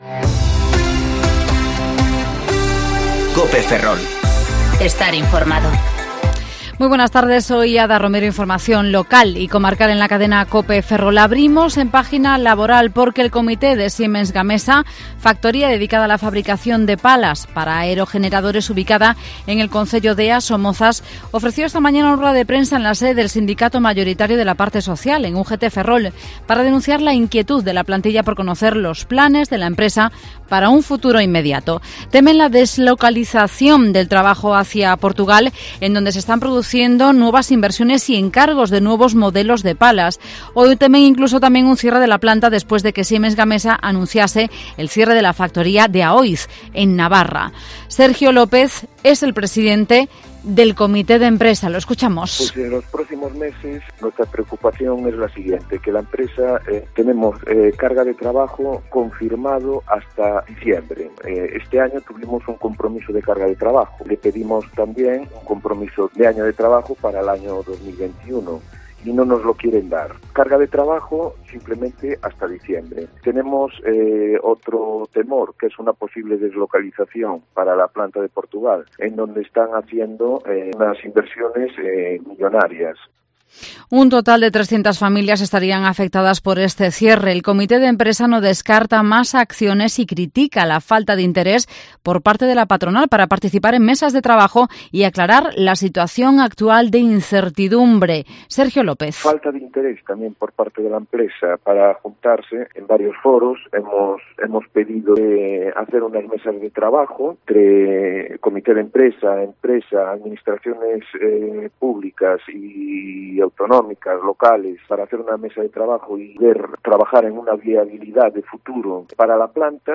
Informativo Mediodía COPE Ferrol 4/09/2020 ( De 14.20 a 14.30 horas)